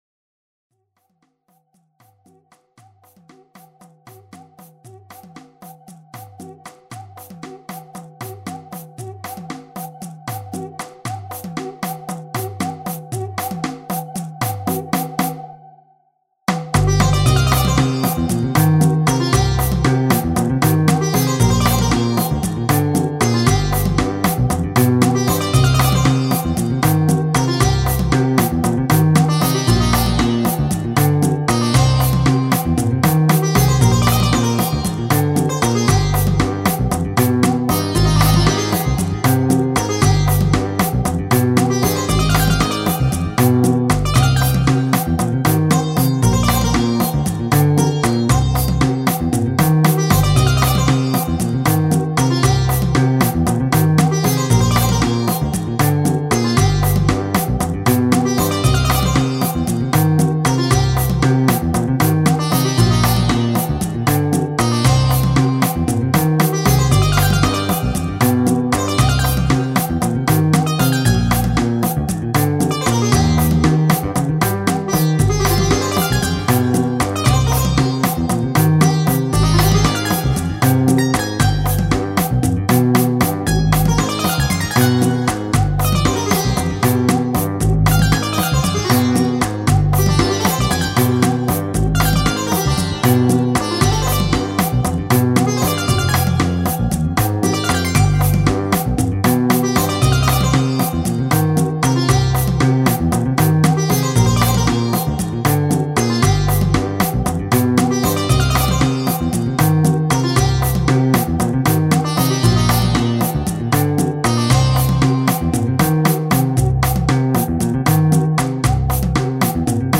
ファンタジーロング民族